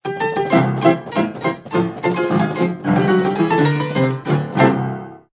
Piano_Tuna.ogg